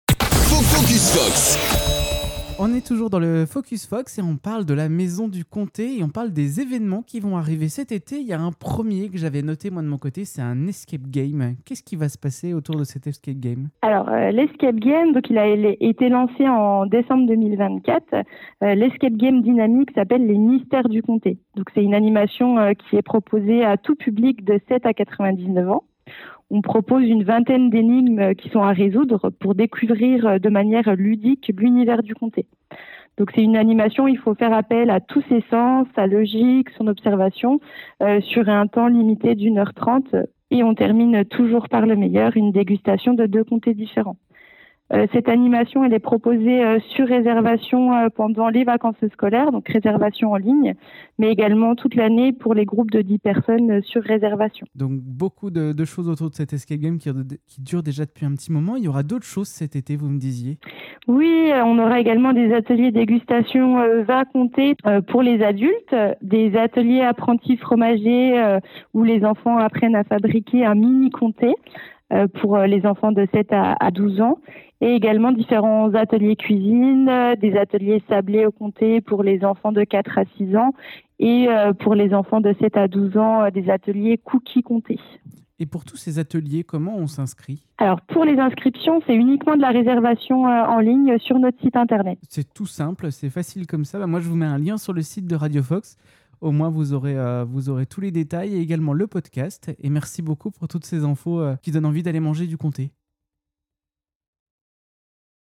Dans une seconde partie de notre échange, l’équipe nous présente cette animation originale qui mêle jeu d’énigmes, exploration, et culture fromagère.